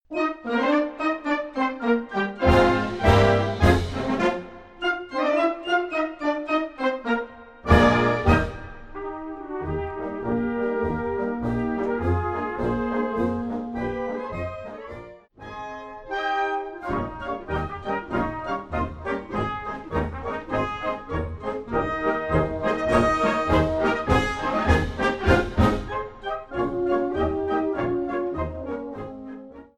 Gattung: Marsch Besetzung: Blasorchester PDF